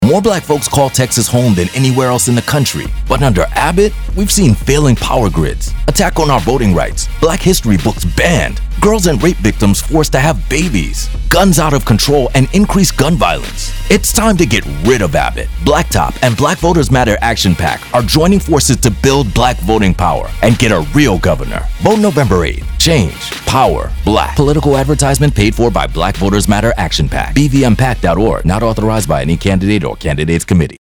African American, Diverse Political Commercial Voice Over
Profound. Resonant. Real | Voiceovers